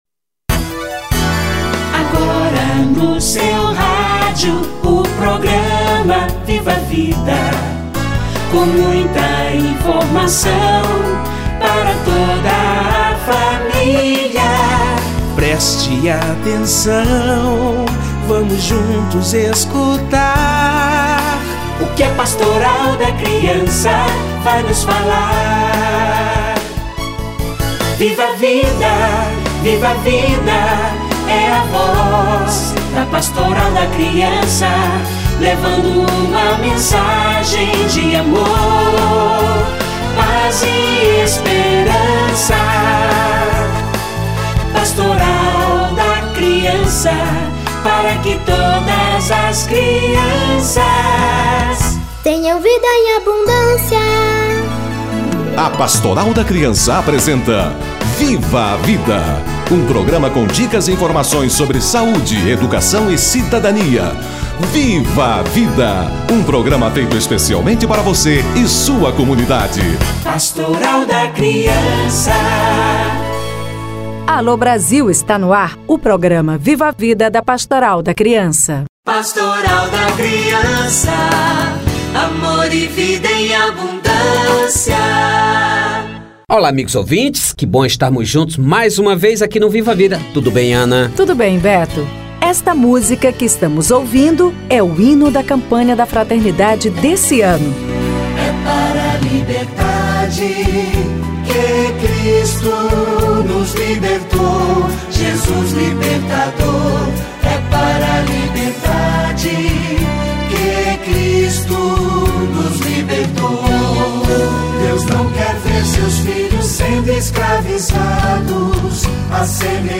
Campanha da Fraternidade - Entrevista